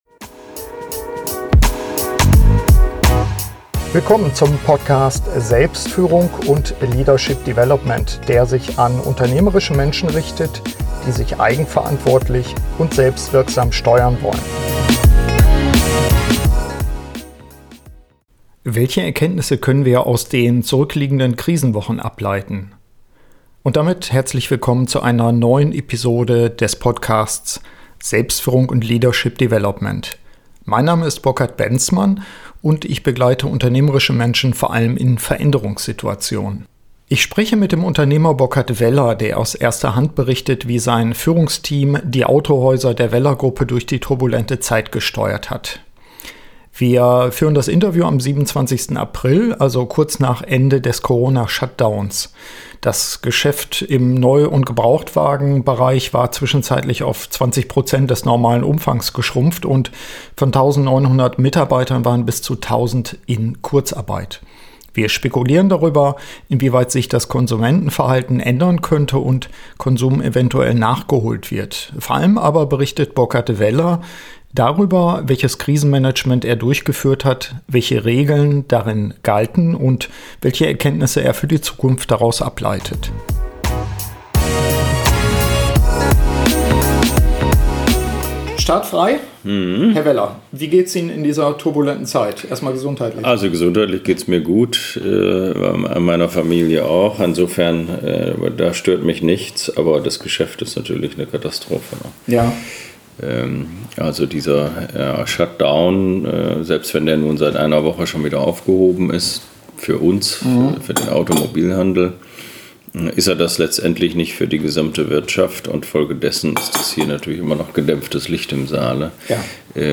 SF132 Führung: Erkenntnisse aus der Krise - Update-Interview